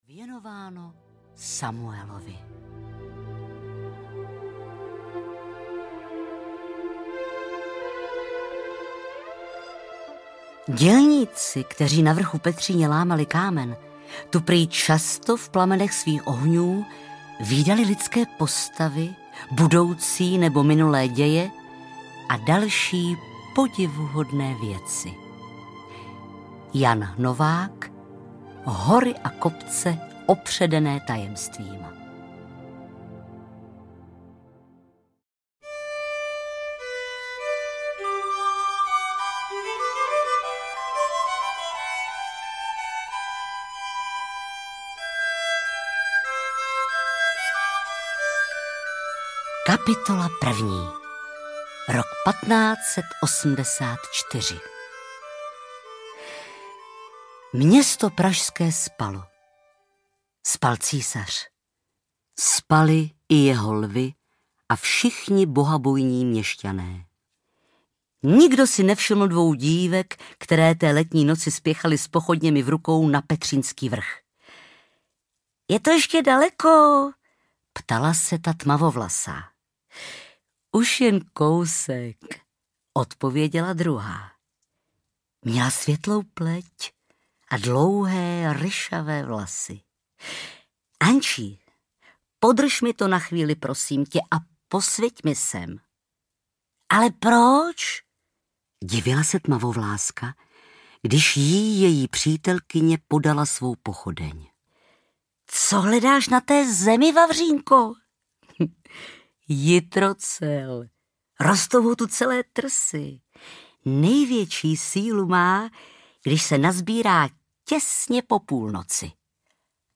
Lvářka audiokniha
Ukázka z knihy
• InterpretBarbora Hrzánová